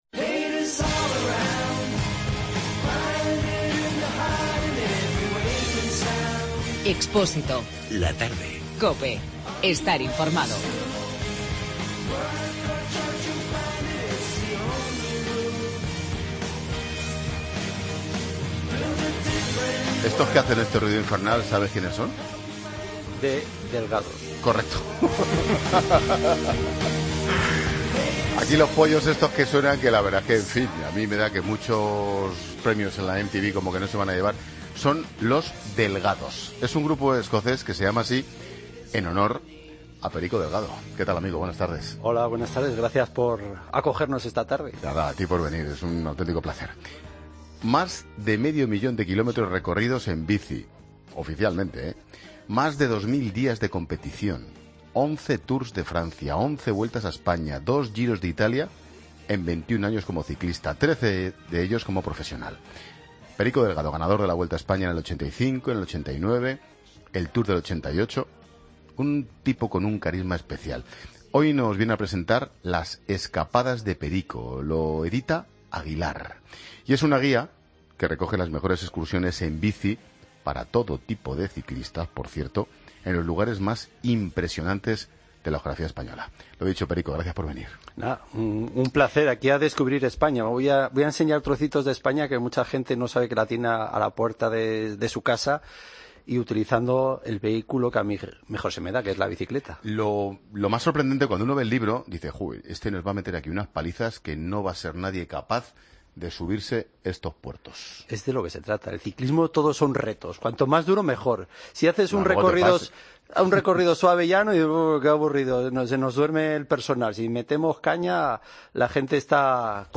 AUDIO: Escucha la entrevista al ex ciclista Perico Delgado, autor del libro 'Las escapadas de Perico' en 'La Tarde'